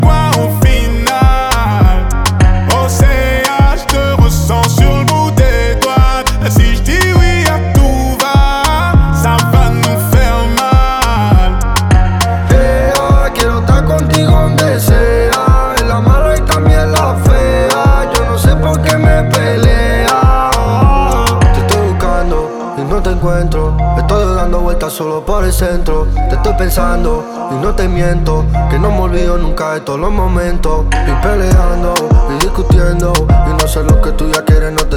Жанр: Латиноамериканская музыка / Русские
# Urbano latino